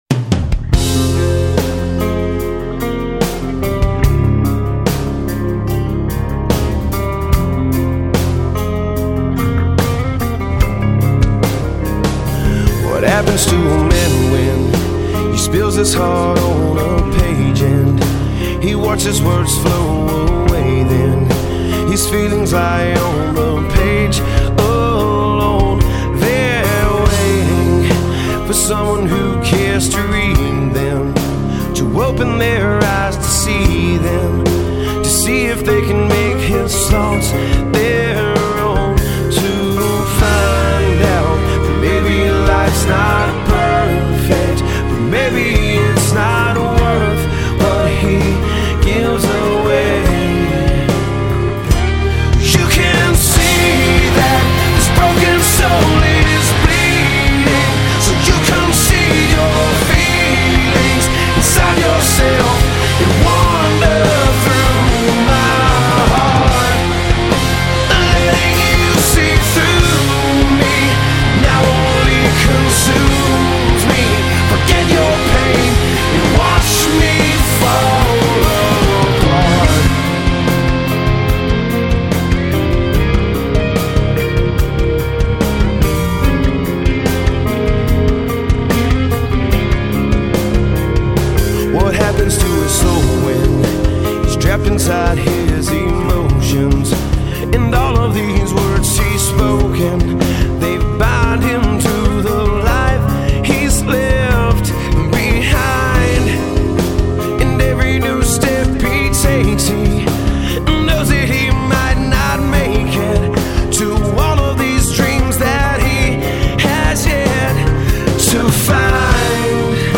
Жанр: rock